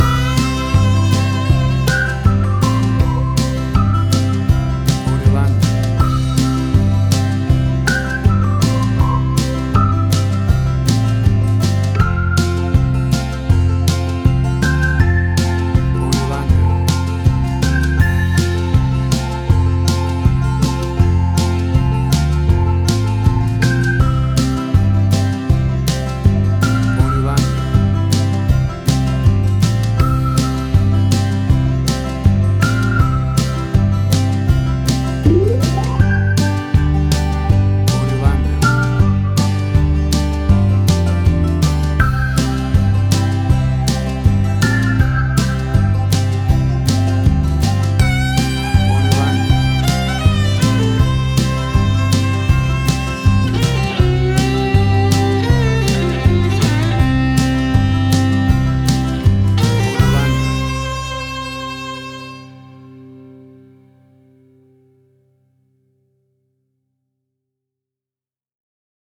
Banjo country music for all country music lovers!
WAV Sample Rate: 16-Bit stereo, 44.1 kHz
Tempo (BPM): 80